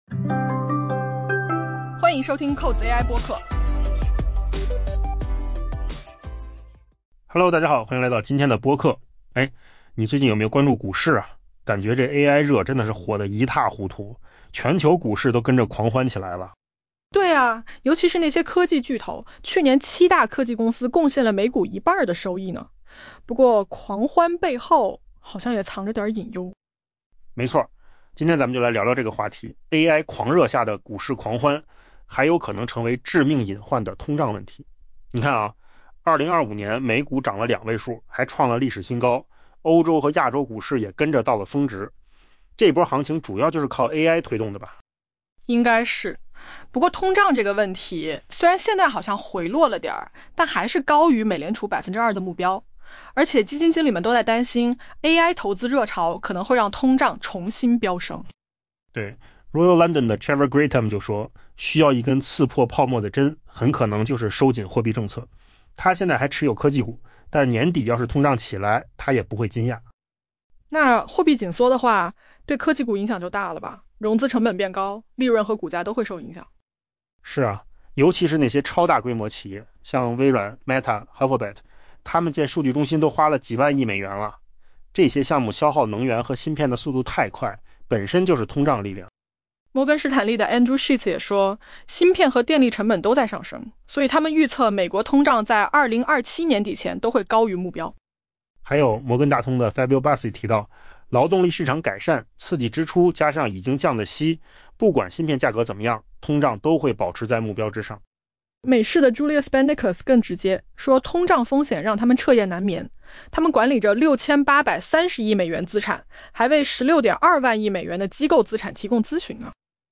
AI 播客：换个方式听新闻 下载 mp3 音频由扣子空间生成 2026 年伊始，全球股市在人工智能 （AI） 狂热的推动下高歌猛进，但 市场或许正在无视一个可能让这场派对戛然而止的巨大威胁：部分由科技投资热潮引发的通胀飙升。